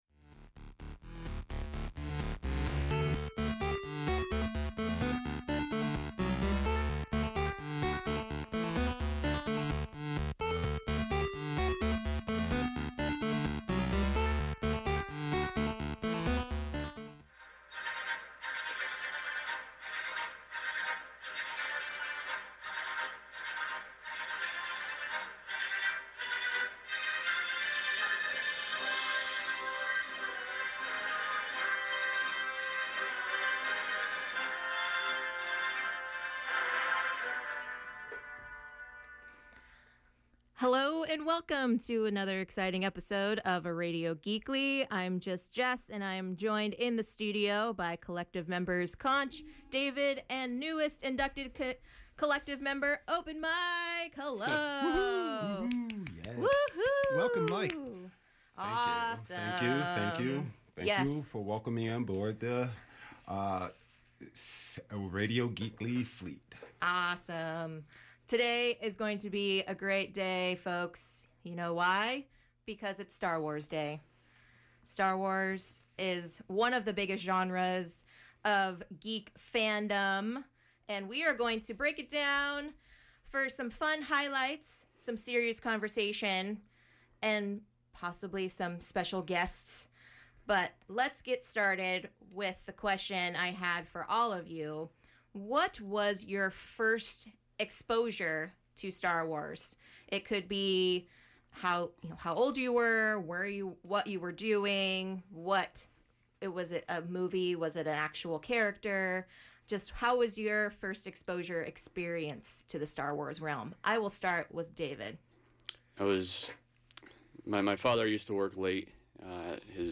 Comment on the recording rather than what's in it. Join the collective as they discuss one of the most popular genres in geekdom, Star Wars! We'll share our opinions, give our thoughts on the upcoming films, and take guest calls.